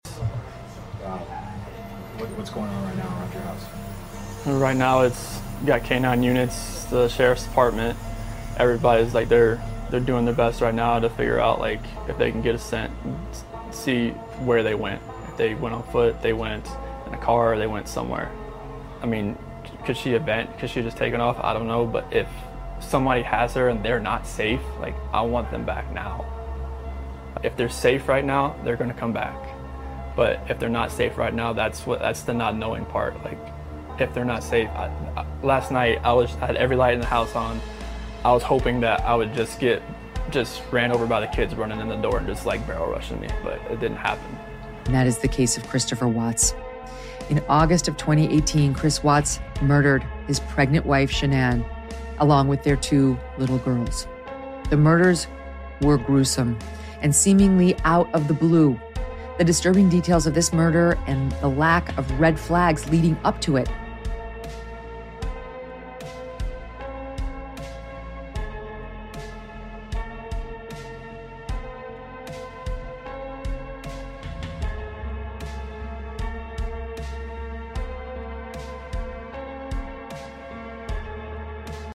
Chilling interview Chris Watts gave sound effects free download